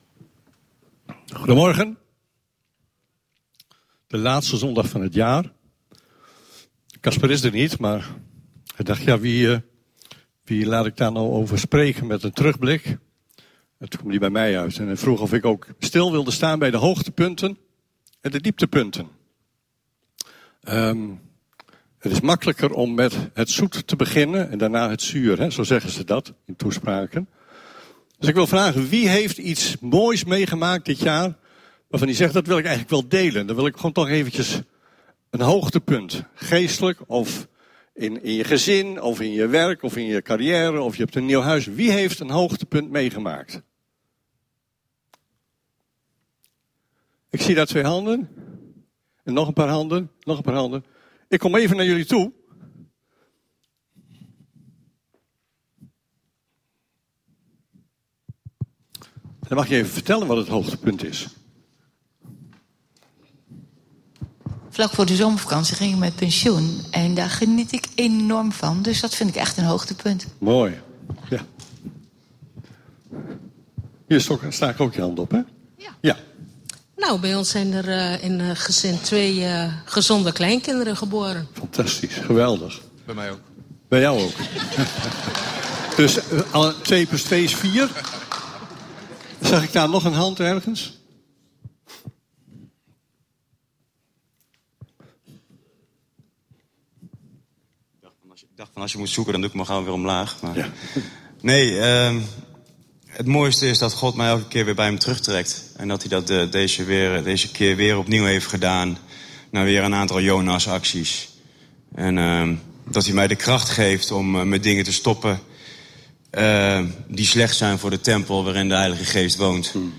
Luister hier gratis 200+ audio-opnames van preken tijdens onze evangelische diensten en blijf verbonden met Jezus!